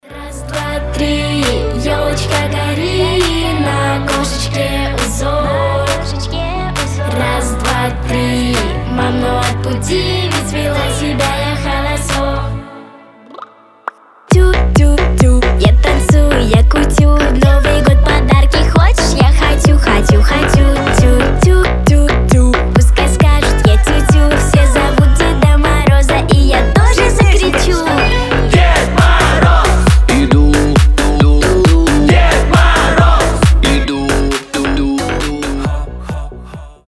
• Качество: 320, Stereo
мужской голос
детский голос